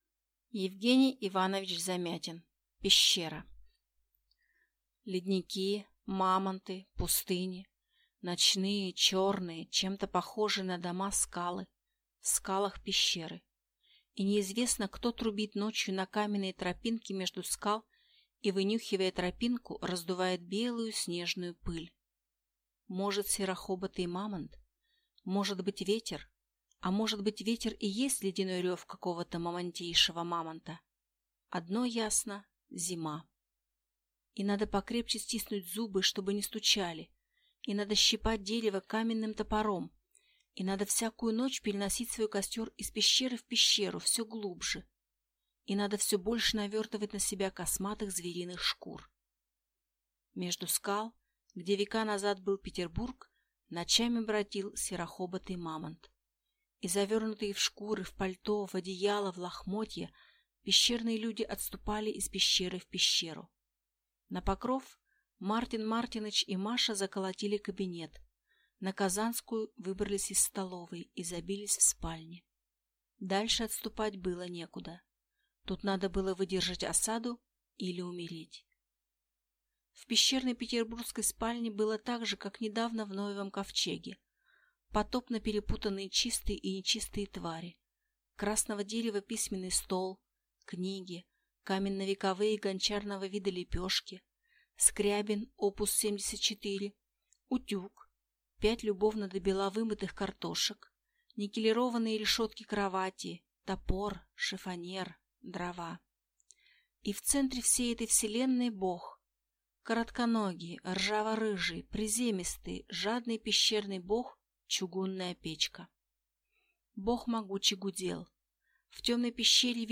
Аудиокнига Пещера | Библиотека аудиокниг